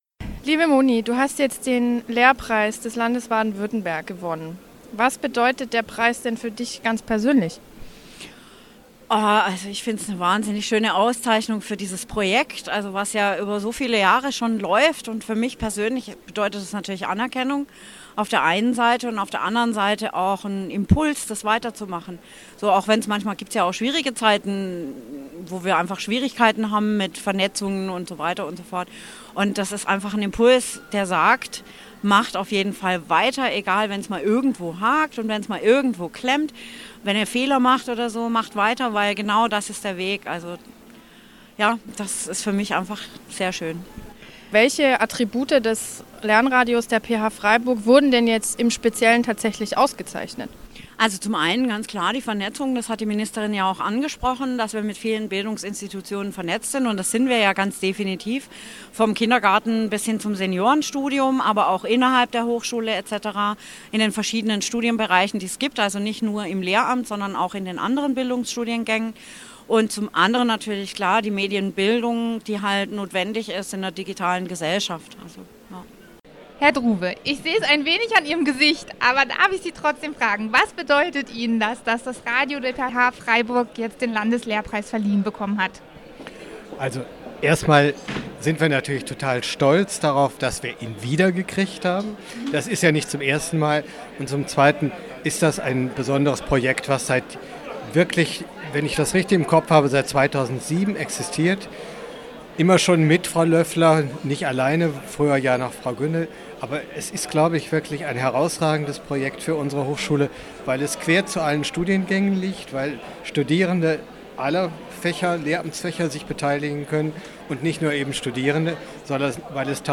Dieser Eintrag wurde veröffentlicht unter Interview Politik Podcast-Archiv der PH-Freiburg und verschlagwortet mit Bildung deutsch am von